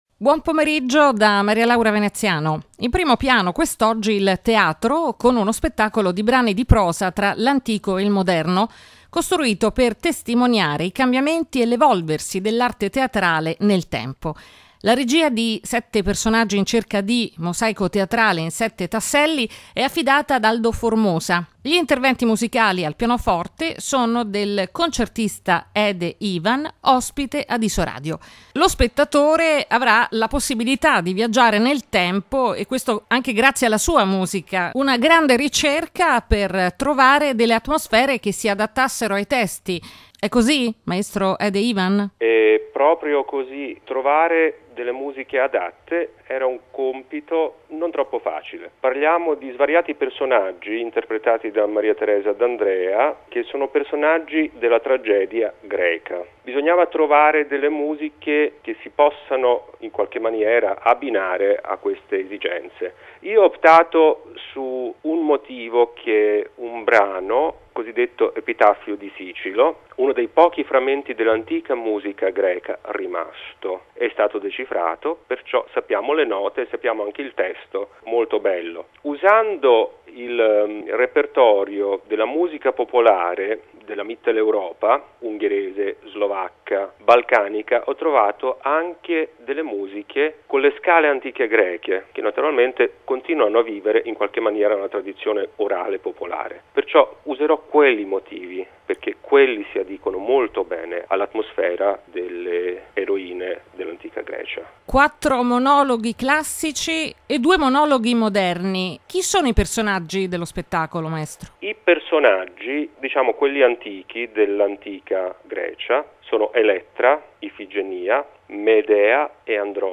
Intervista: